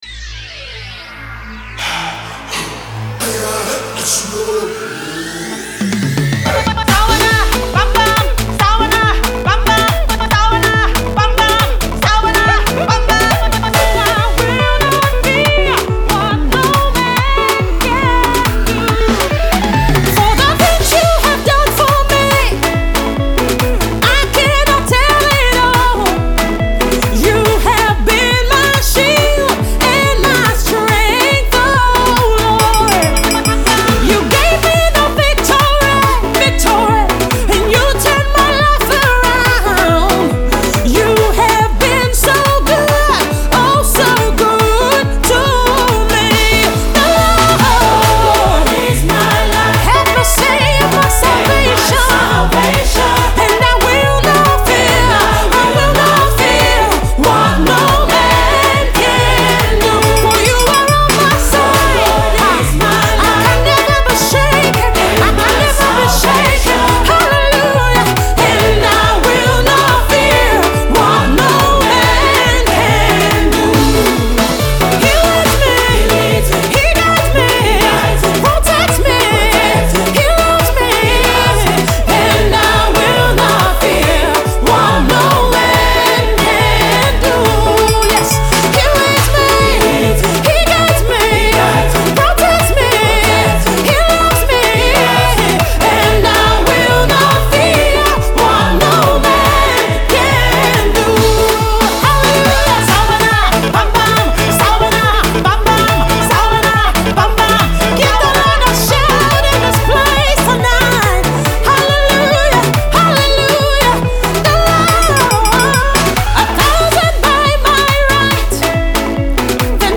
dance jam
You’ll be needing your dancing shoes for this one.